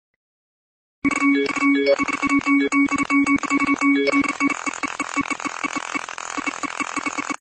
100 notifications within a minute